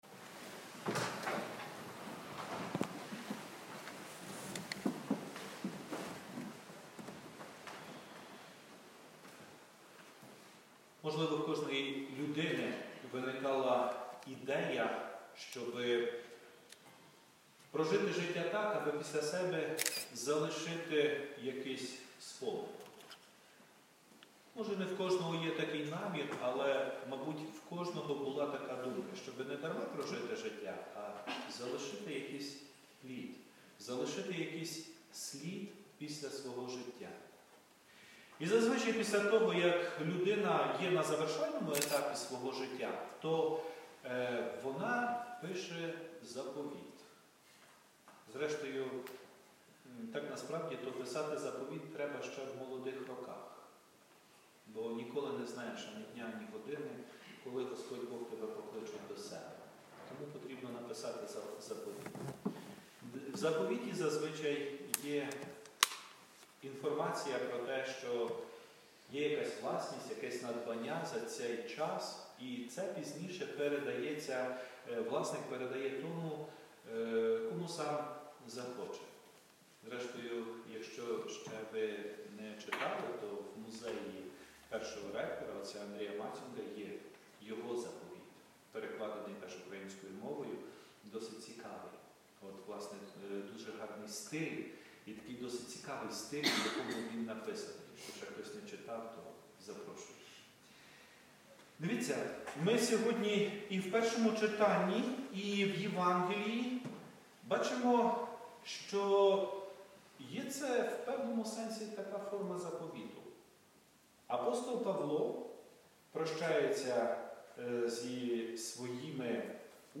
Проповідь